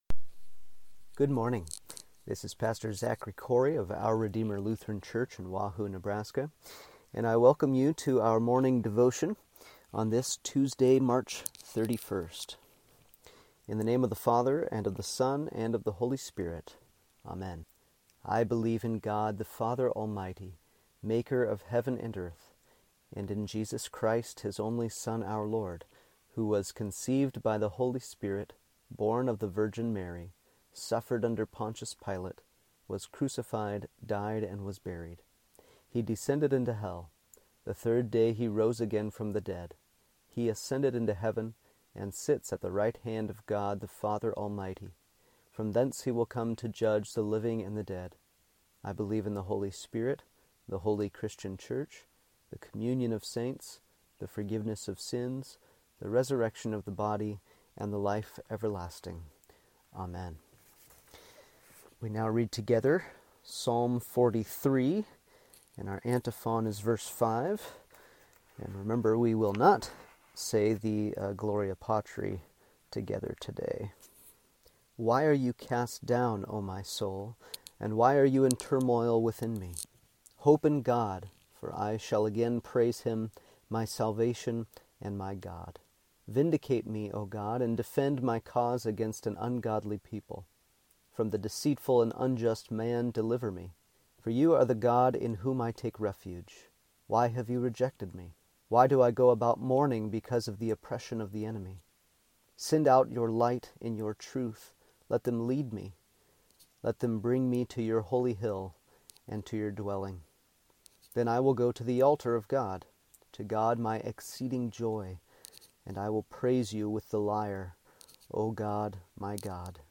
Morning Devotion for Tuesday, March 31st
Here’s Tuesday’s morning devotion.